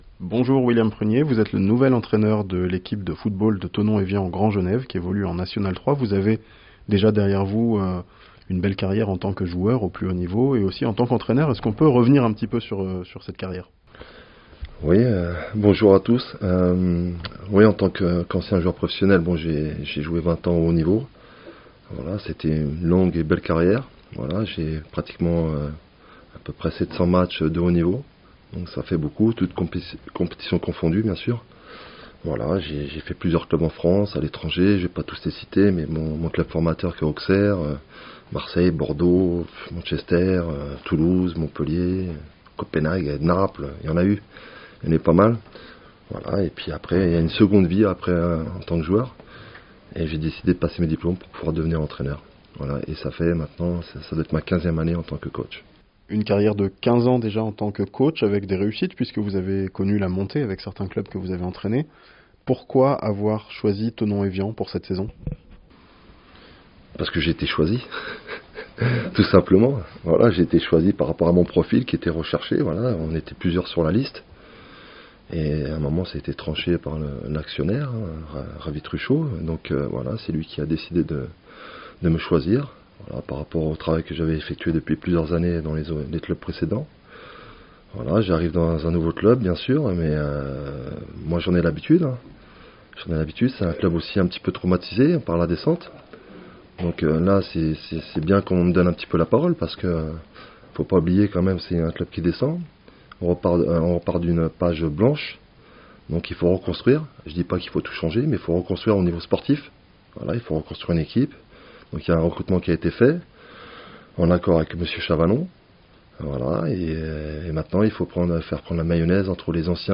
Rencontre avec William Prunier, nouvel entraîneur du Thonon Evian Grand Genève FC (interview)